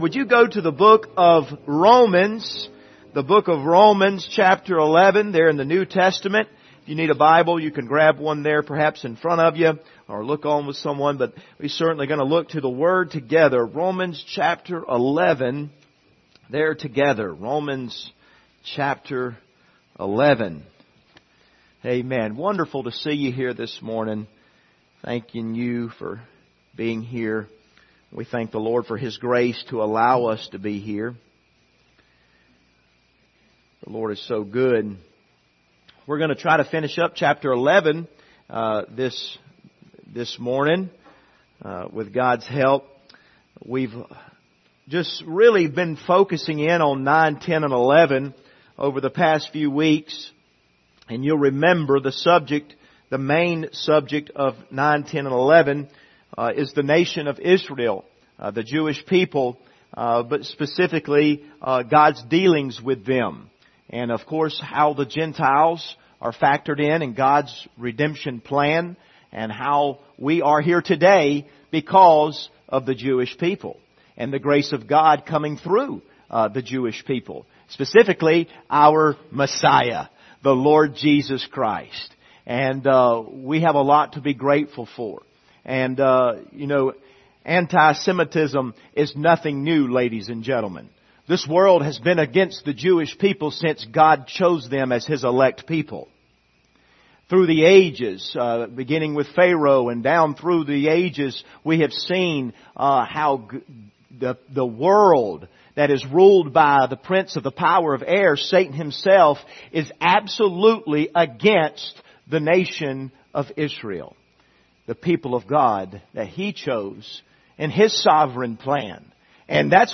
Passage: Romans 11:23-36 Service Type: Sunday Morning